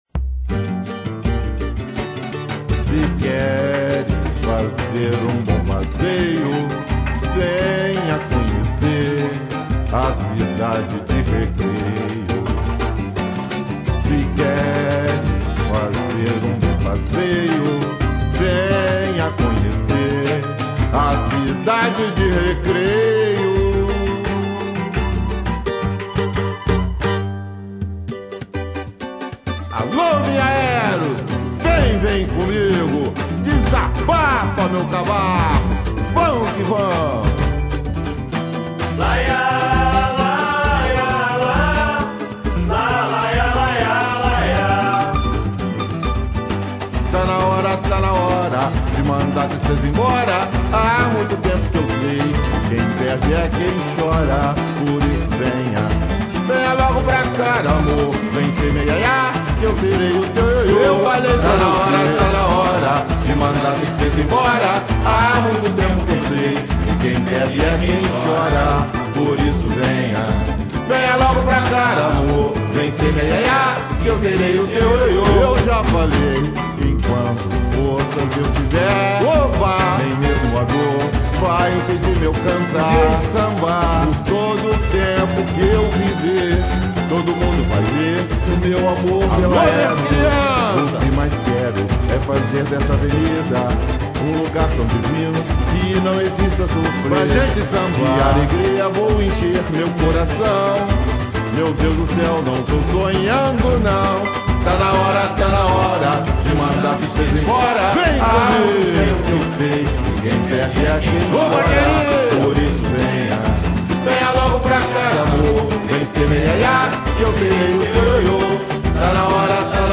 ERUS apresenta áudio do samba-enredo 2017